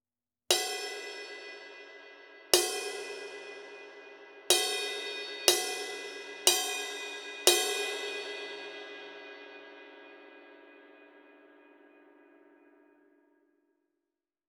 20" Kerope Medium Thin Low Ride
Kerope は、何十年も古い「K」サウンドを追い求めてきた音の専門家たちも共感する豊かさと温かさを持ち、他のシンバルにはない複雑さと質感を音楽に吹き込みます。
KEROPE_20_Medium_Thin_Low_Bell.wav